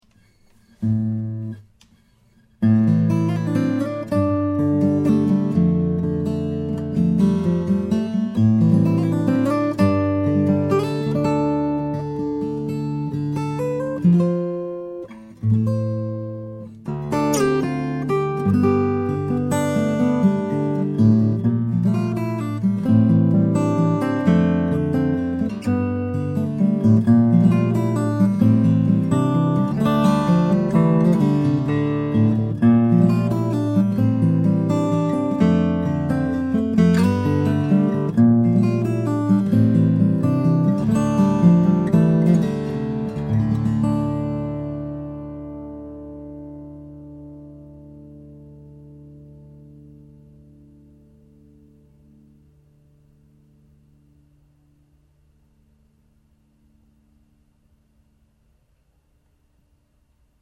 Bonjour, je recherche ce genre d'instrus